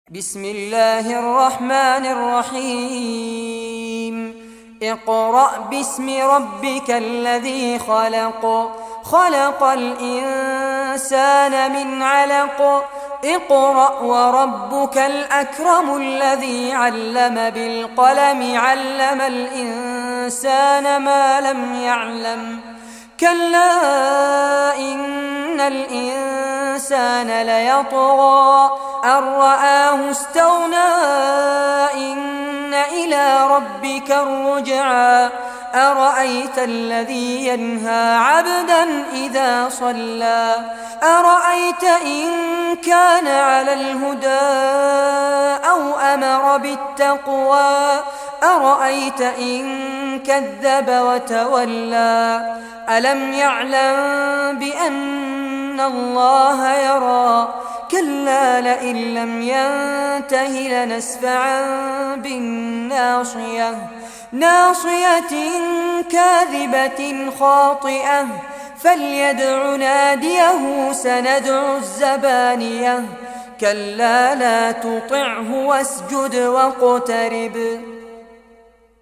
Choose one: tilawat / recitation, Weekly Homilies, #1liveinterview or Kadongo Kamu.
tilawat / recitation